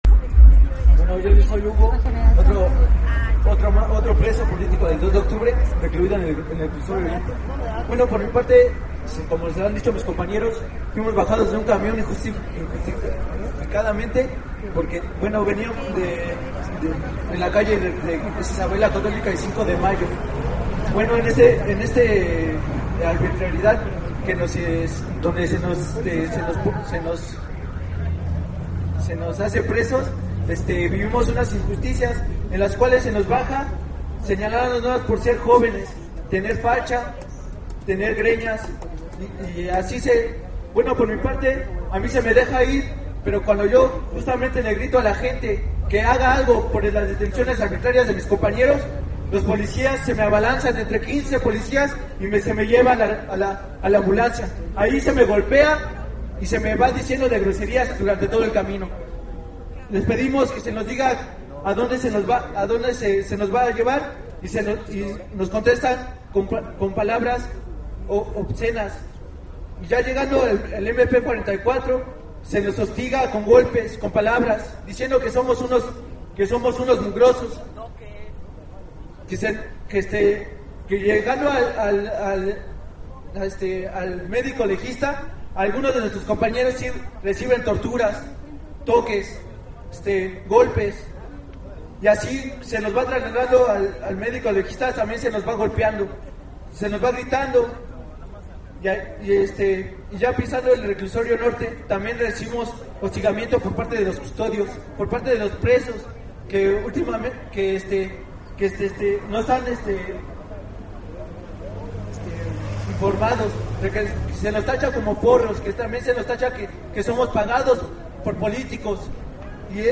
Pasado del medio día, inició el mitin que daría paso a la instalación de las carpas para iniciar la huelga de hambre.
Participación de procesado político